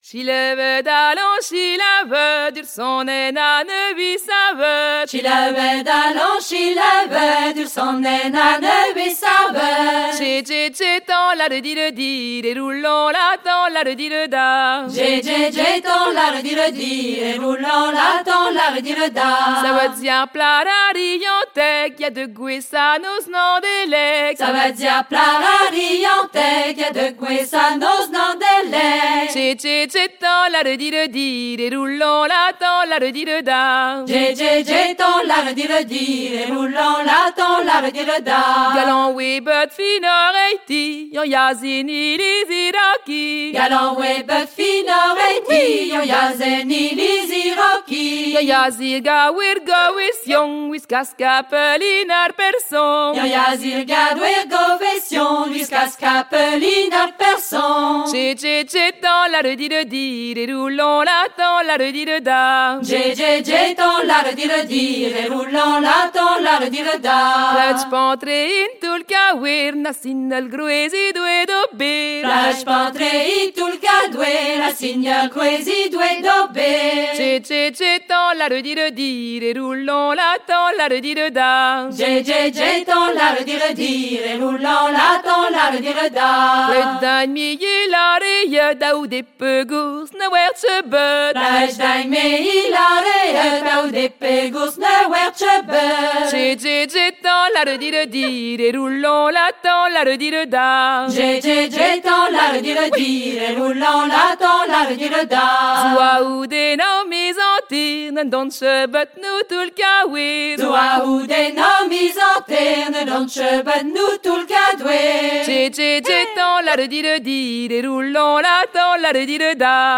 Deux Kas a-barh très connus proposés au choix du groupe : Ar plah a-Rianteg et Nag él ma pasen pont er velin